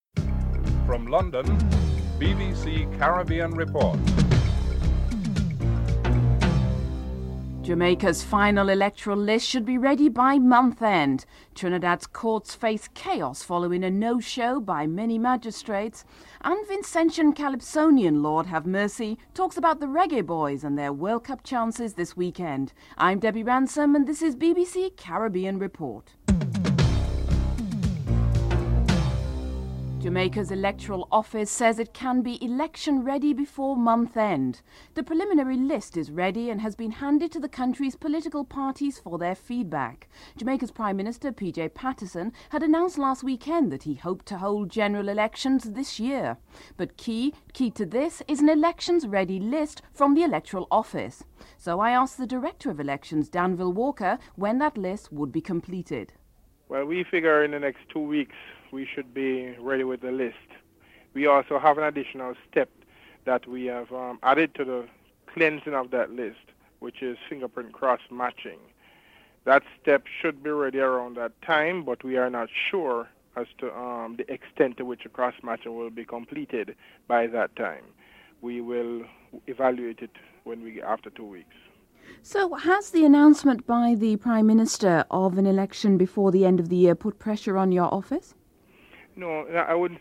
1. Headlines (00:00-00:28)
Danville Walker, Director of Elections is interviewed (00:29-02:53)